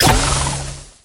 tanuki_jess_fire_01.ogg